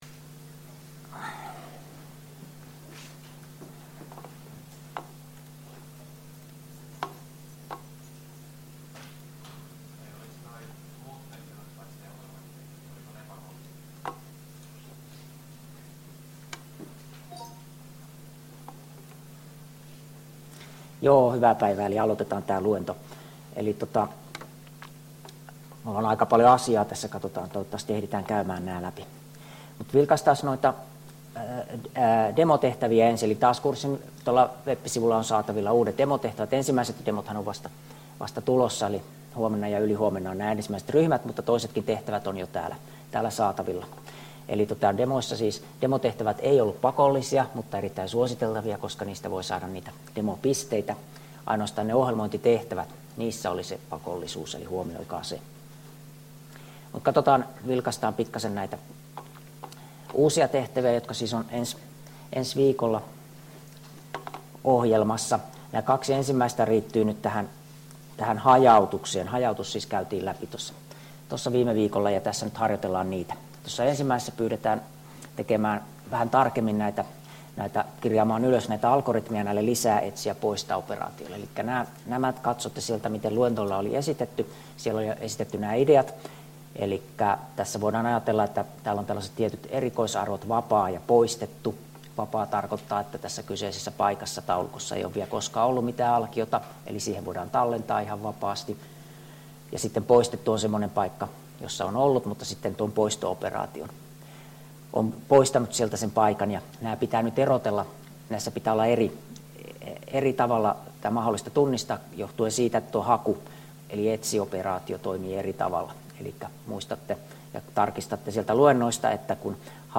Luento 5 — Moniviestin